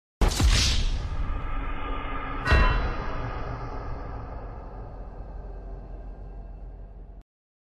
gta-v-wastedbusted-gaming-sound-effect-hd.mp3